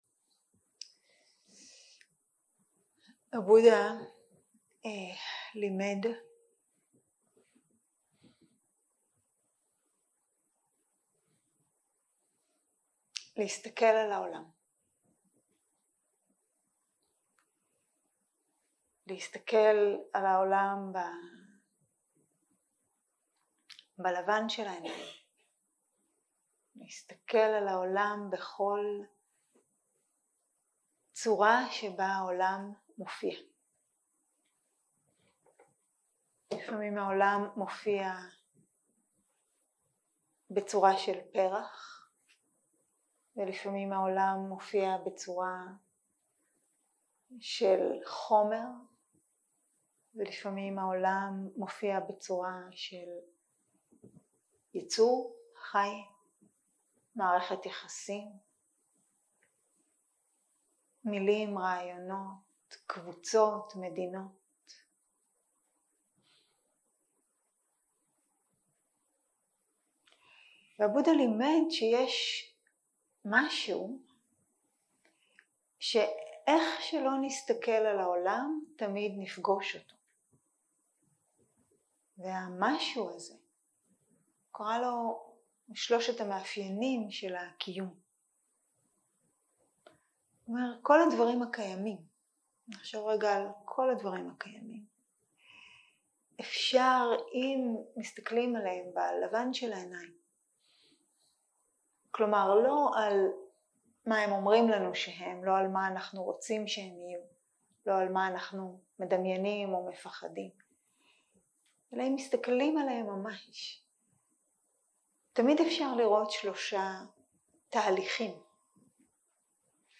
יום 2 - ערב - שיחת דהרמה - צ'רלי בראון, קנדי קראש וציפרלקס - הקלטה 4.
סוג ההקלטה: שיחות דהרמה